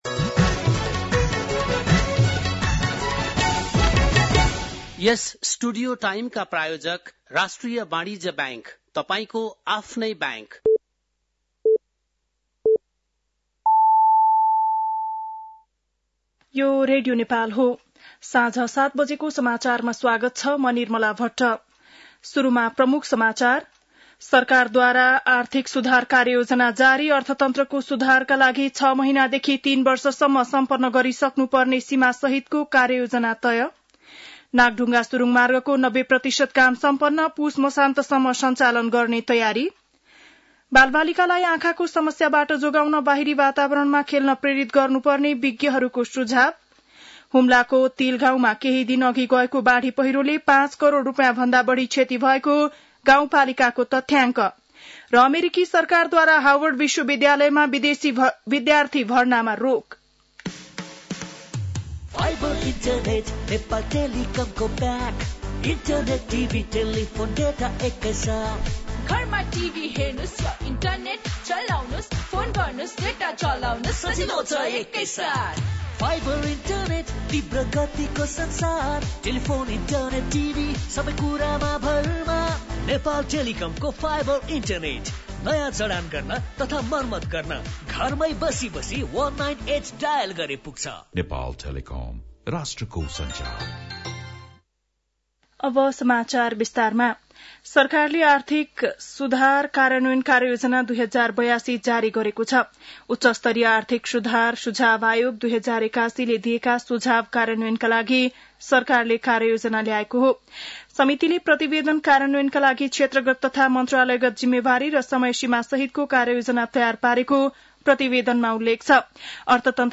बेलुकी ७ बजेको नेपाली समाचार : ९ जेठ , २०८२
7.-pm-nepali-news-4.mp3